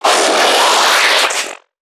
NPC_Creatures_Vocalisations_Infected [3].wav